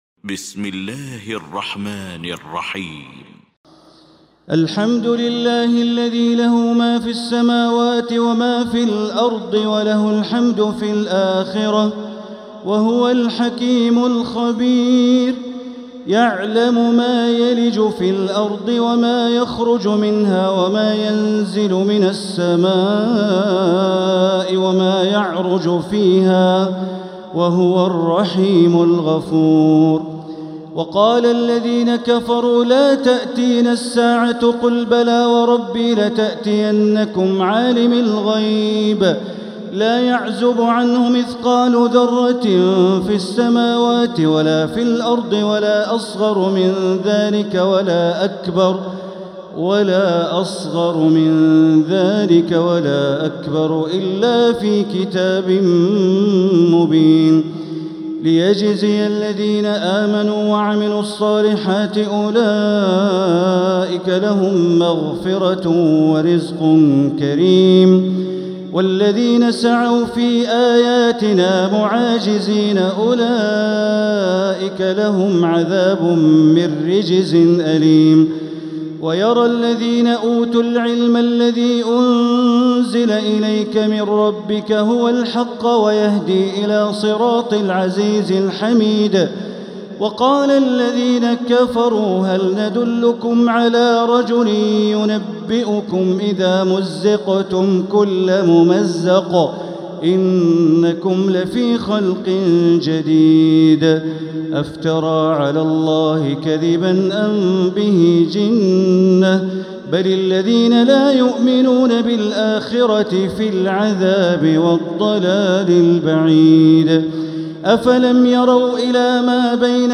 المكان: المسجد الحرام الشيخ: معالي الشيخ أ.د. بندر بليلة معالي الشيخ أ.د. بندر بليلة سبأ